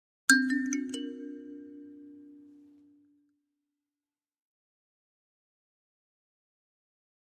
Kalimba, Accent, Type 5 - Major, Arpeggio, Ascending